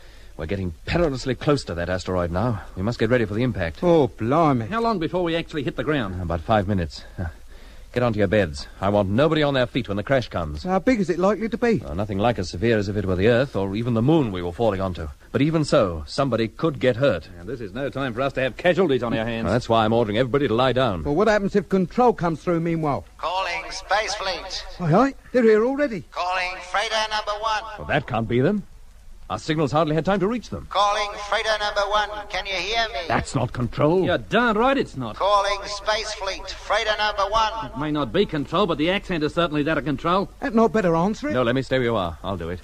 Natuurlijk klinkt de stem van de controle anders, als die wordt ingevuld door een Martiaan.